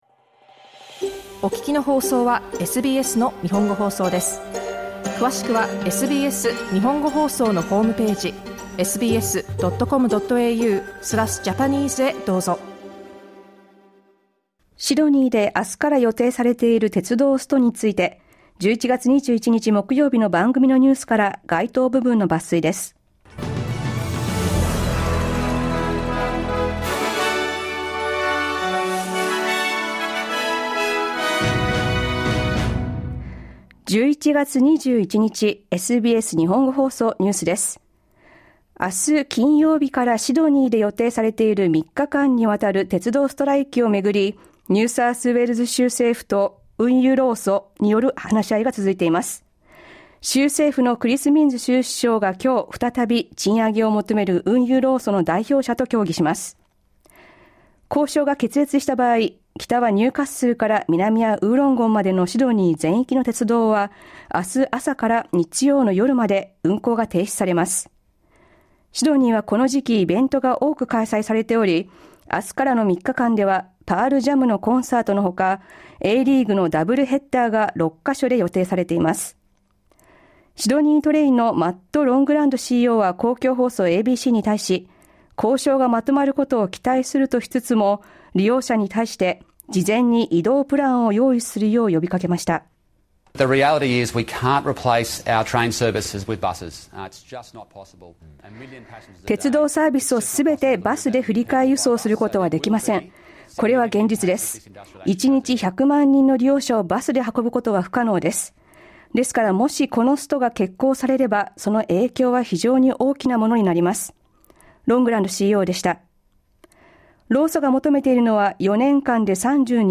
シドニーで明日22日から予定されている３日間の鉄道ストライキについて、21日放送のラジオ番組からニュースを抜粋しました。